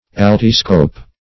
Search Result for " altiscope" : The Collaborative International Dictionary of English v.0.48: Altiscope \Al"ti*scope\, n. [L. altus high + Gr.